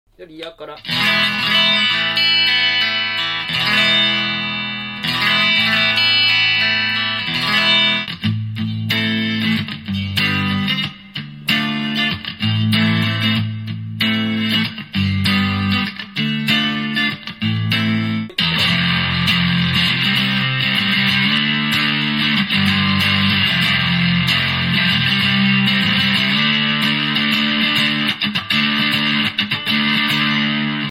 1977年製のFenderストラトキャスター🎸生まれ年のギター